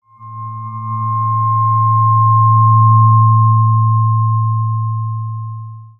Handcrafted 111Hz Weighted Tuning Fork made from high-grade aluminium, providing a long and enduring tone.
A handcrafted 111Hz tuning fork, made from high-grade aluminium, that provides long and enduring notes.
111Hz-Tuning-Fork.mp3